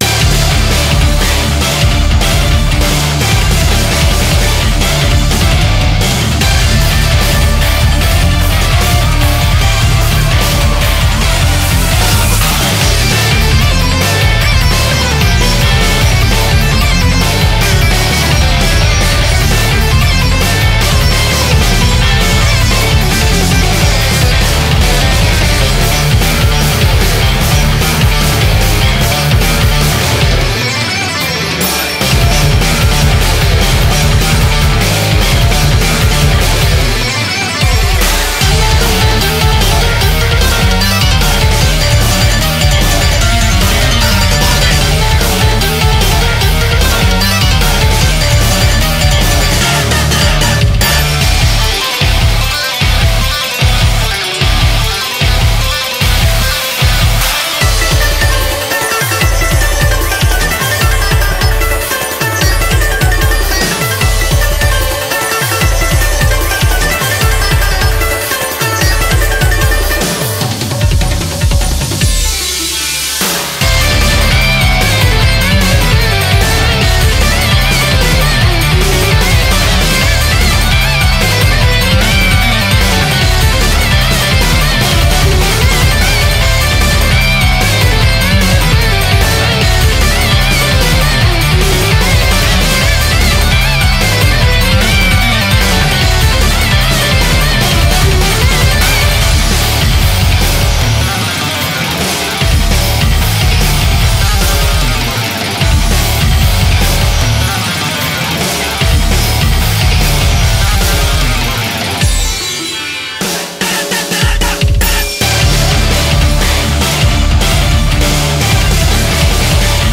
BPM150
Audio QualityPerfect (High Quality)